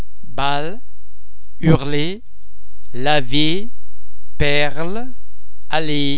The French [l] and [ll] are normally pronounced a single [l] sound, as in the English lullaby, all, low, pal etc.
[ l ] (anywhere in the word)- as in